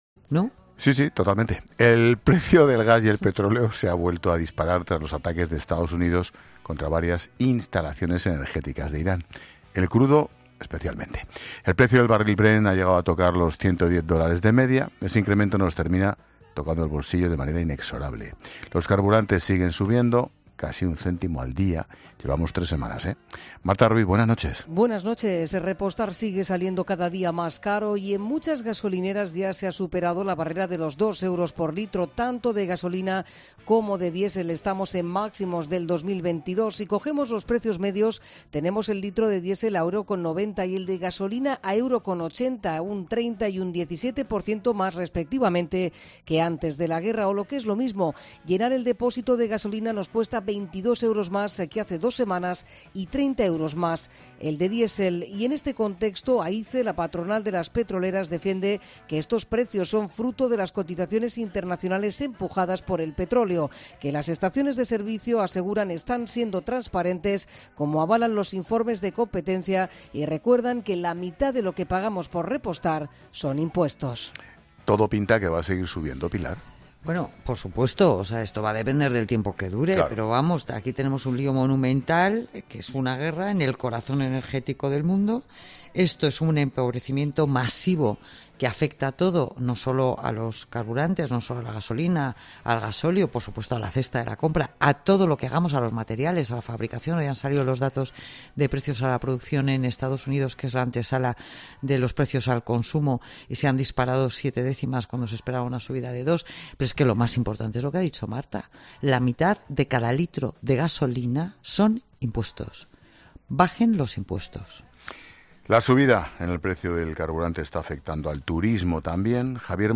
Expósito aprende en Clases de Economía de La Linterna con la experta económica y directora de Mediodía COPE, Pilar García de la Granja, sobre el disparo del precio del gas y el petróleo por ataques en Irán